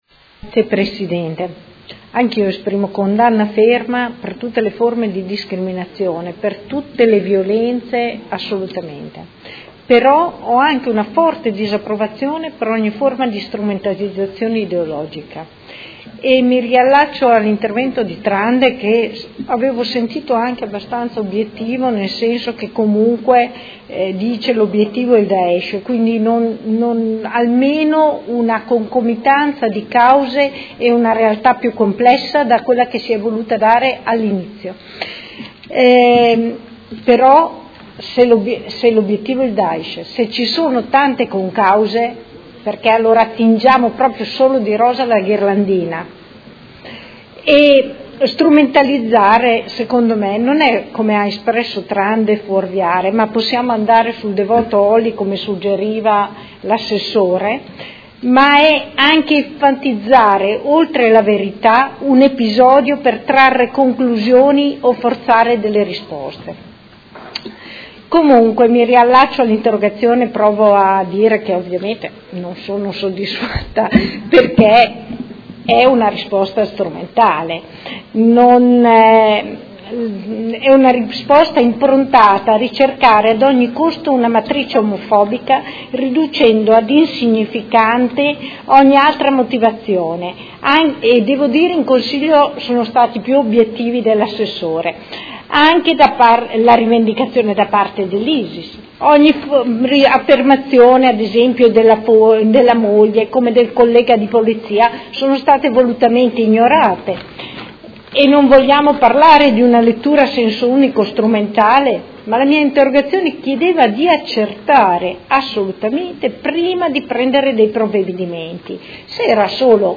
Luigia Santoro — Sito Audio Consiglio Comunale
Seduta del 14/07/2016 Dibattito congiunto su Interrogazione della Consigliera Santoro (IDEA-Popolari Liberali) avente per oggetto: Strage di Orlando: strumentalizzazioni o prudenza? e Ordine del Giorno presentato dai Consiglieri Cugusi (SEL), Chincarini (PerMeModena), Rocco (FAS-S.I.), Scardozzi (M5S) avente per oggetto: Strage di Orlando